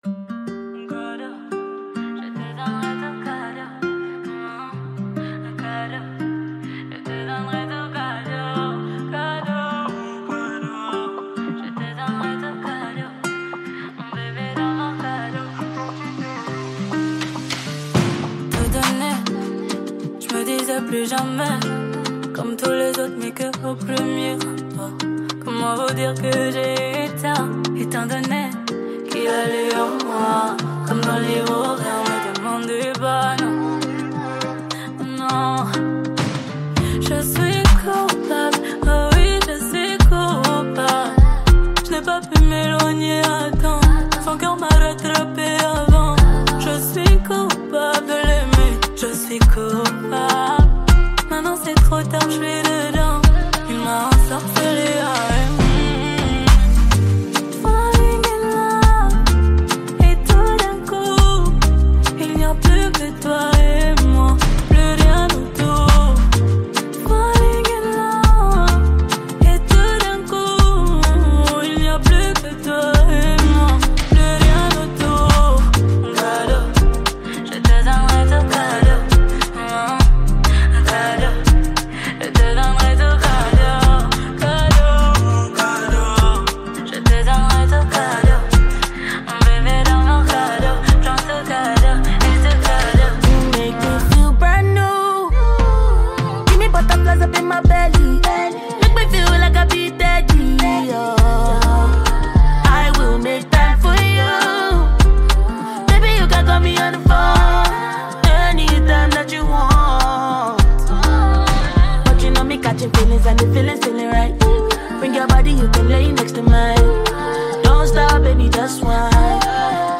featuring the powerful vocals